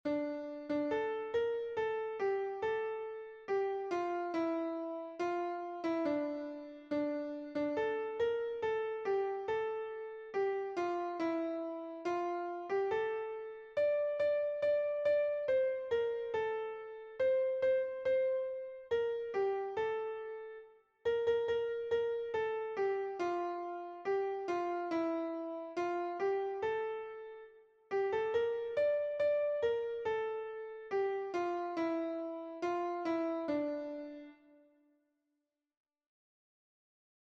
Melodías de bertsos - Ver ficha   Más información sobre esta sección
Hamarreko txikia (hg) / Bost puntuko txikia (ip)
7 / 6A / 7 / 6A /7 / 6A / 7 / 6A / 7 / 6A (hg) | 13A / 13A /13A / 13A /13A (ip)